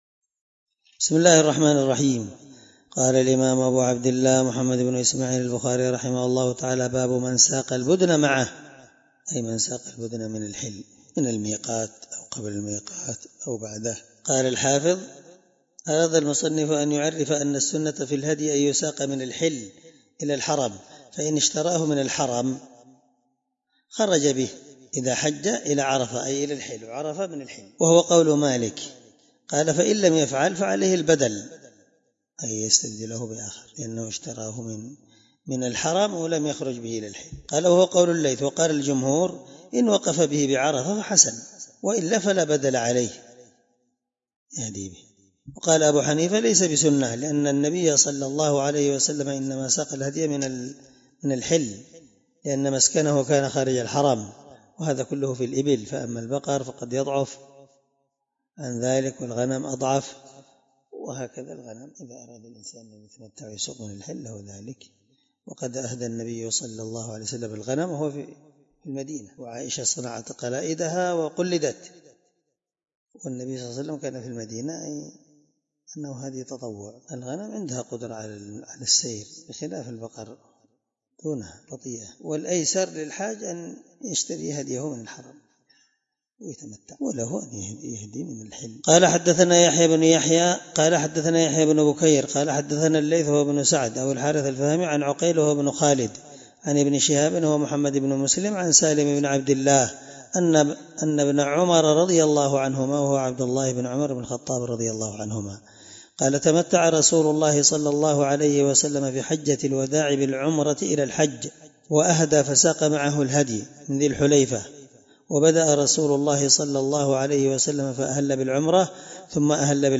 الدرس71 من شرح كتاب الحج حديث رقم(1691-1692 )من صحيح البخاري